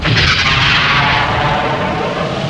Cartoon Explosion 3 Sound Effect Free Download
Cartoon Explosion 3